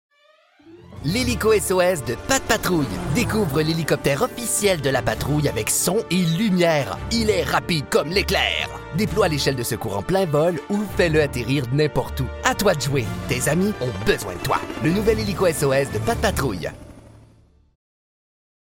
Character / Cartoon
Child Voice Demos
Words that describe my voice are radio, authentic, versatile.